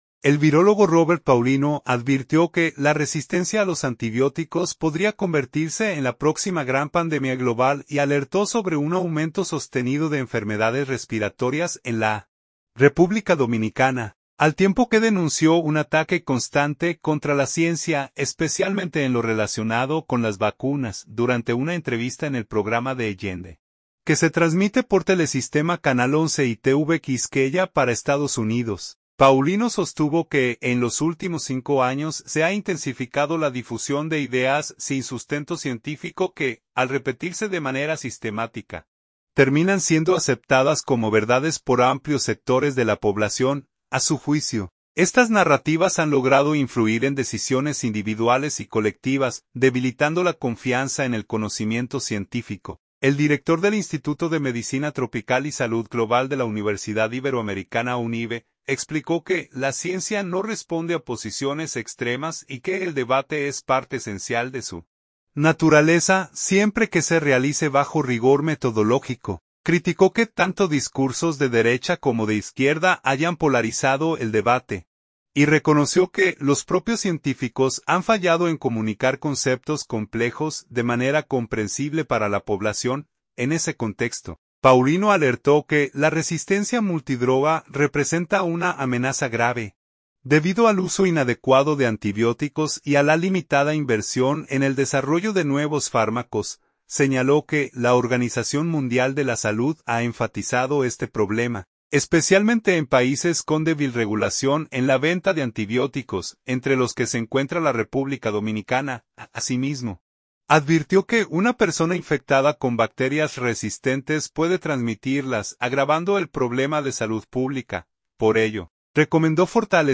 Durante una entrevista en el programa D’AGENDA, que se transmite por Telesistema canal 11 y TV Quisqueya para Estados Unidos